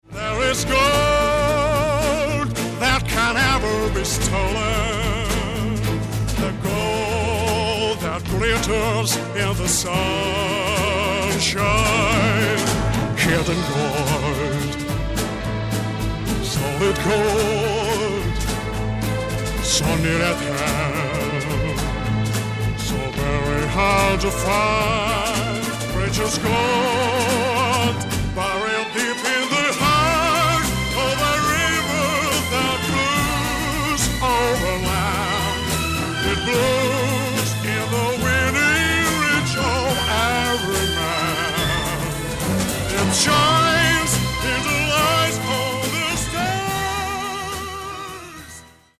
Spaghetti Western epic medium voc.